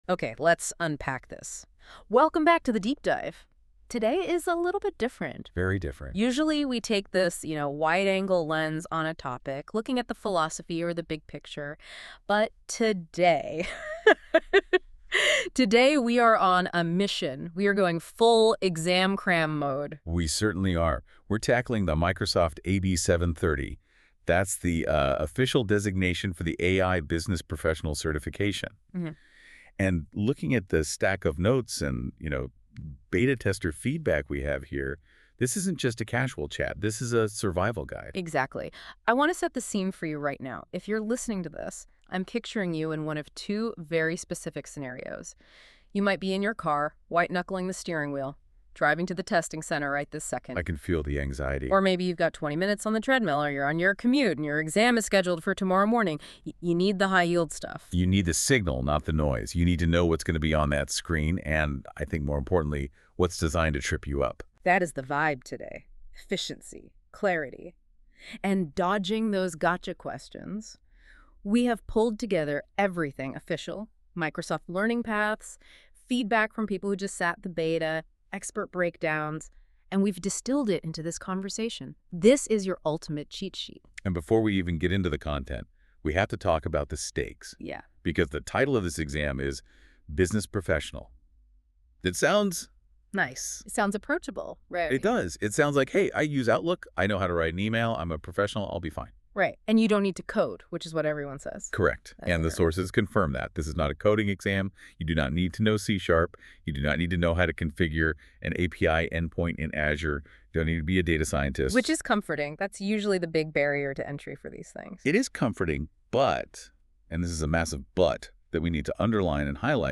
✨ Generated by NotebookLM
Audio Refresher  A podcast-style walkthrough of key exam tactics.